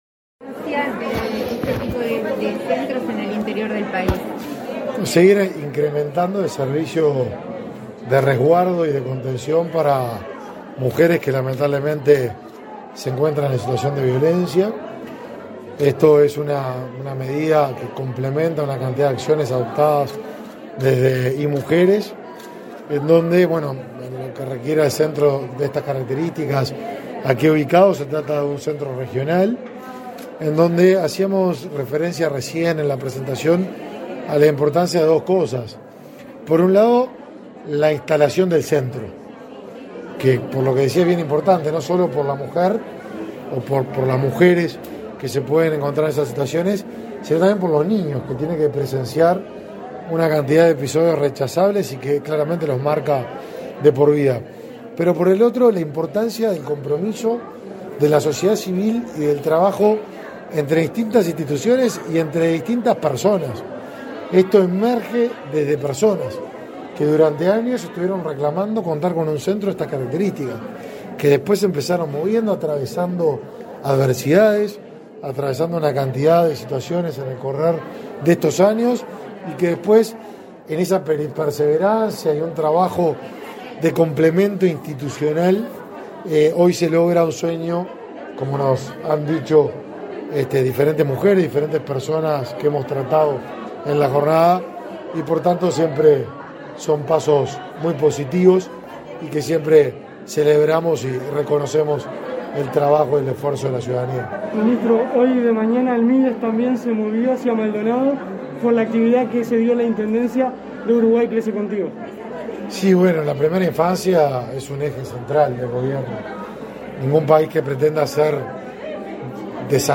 Declaraciones a la prensa del ministro de Desarrollo Social, Martín Lema
Declaraciones a la prensa del ministro de Desarrollo Social, Martín Lema 17/11/2021 Compartir Facebook X Copiar enlace WhatsApp LinkedIn Tras la inauguración del quinto centro de estadía transitoria, de 24 horas, para mujeres en situación de violencia, en la ciudad de San Carlos, este 17 de noviembre, el ministro de Desarrollo Social efectuó declaraciones a la prensa.